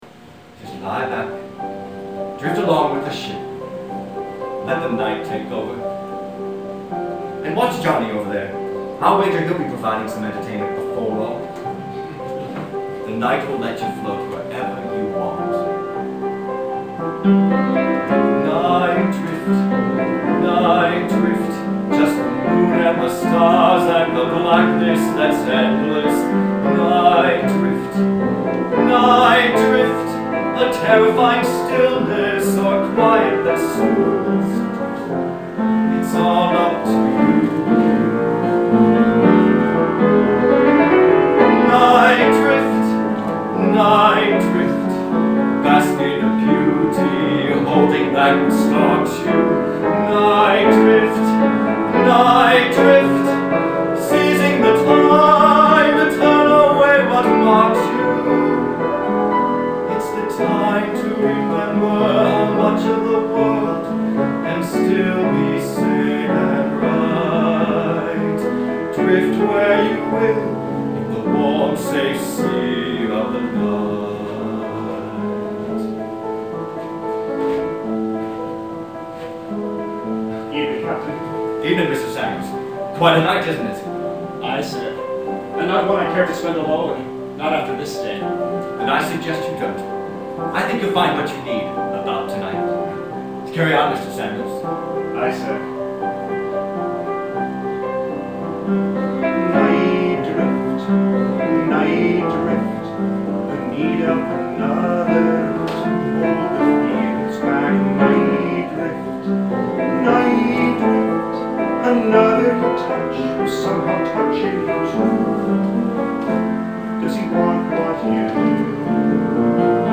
This recording was taken from a live video tape recording of a workshop production of "The Captain's Boy." While the sound quality is considerably less than perfect, we hope you'll have fun listening and get a feel for a few of the musical numbers.